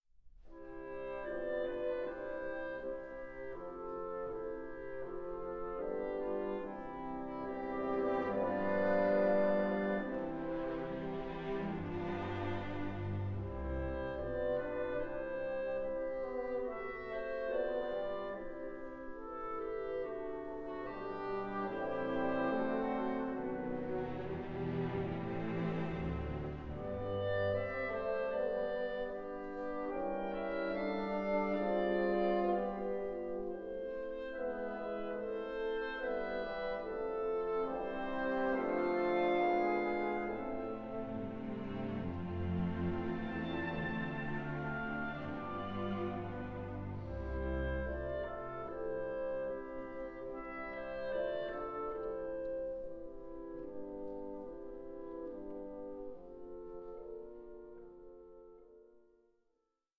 Andante 8:11